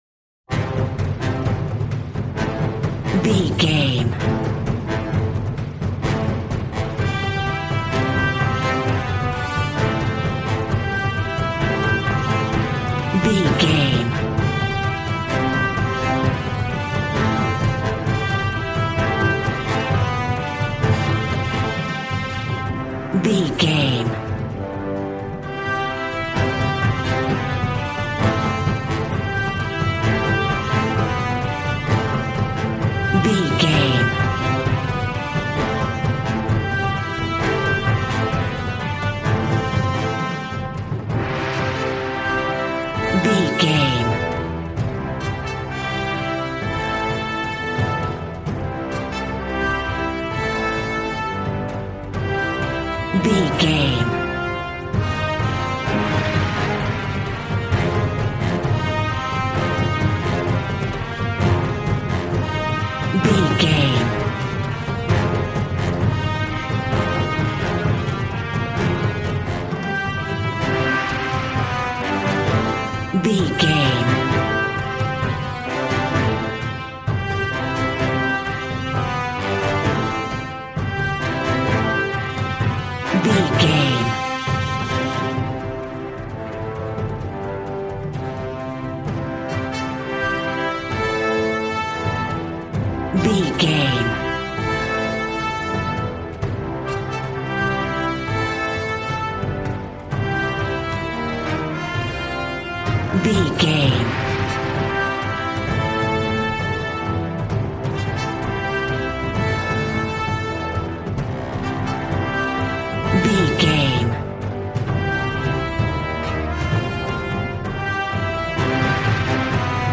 Action and Fantasy music for an epic dramatic world!
Ionian/Major
hard
groovy
drums
bass guitar
electric guitar